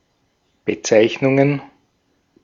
Ääntäminen
Ääntäminen Tuntematon aksentti: IPA: /bəˈtsaɪ̯çnʊŋən/ Haettu sana löytyi näillä lähdekielillä: saksa Käännöksiä ei löytynyt valitulle kohdekielelle. Bezeichnungen on sanan Bezeichnung monikko.